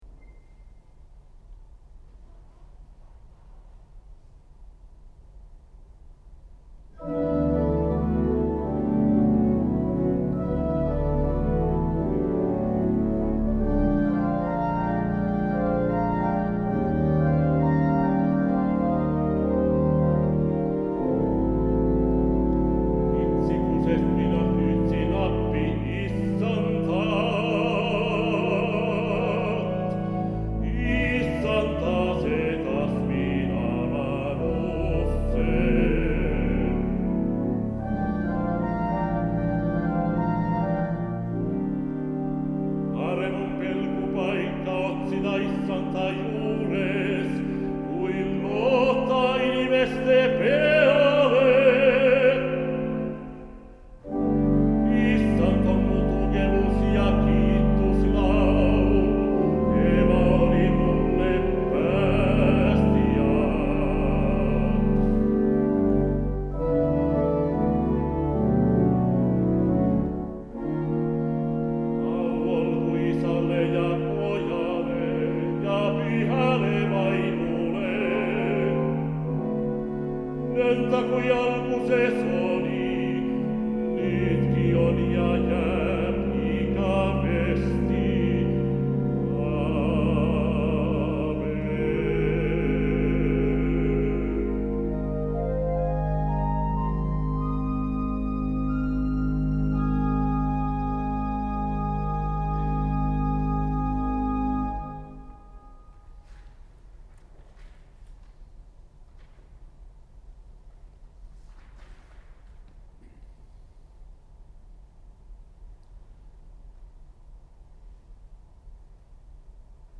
urkukoraali Elu on kui meri